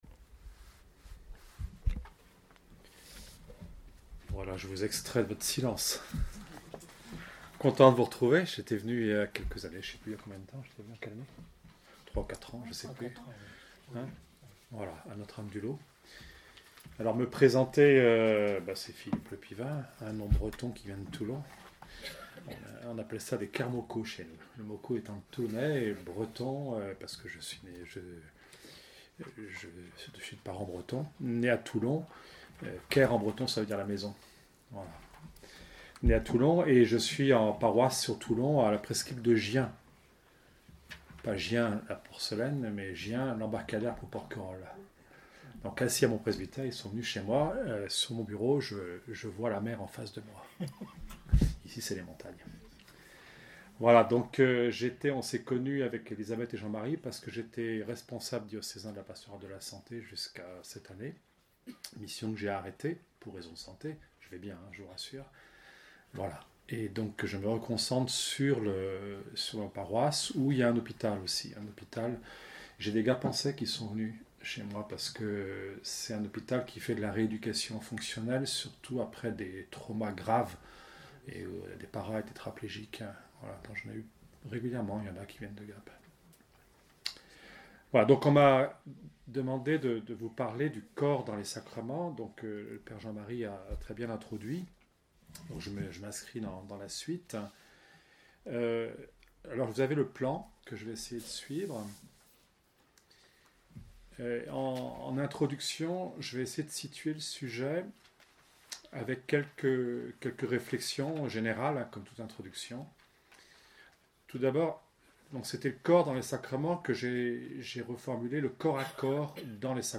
Ce fut le thème de la rencontre diocésaine de la Pastorale de la Santé. Le corps est le lieu de la rencontre, par le corps nous sommes liés les uns aux autres, c’est ce qui instruit la manière de vivre notre mission de visiteur.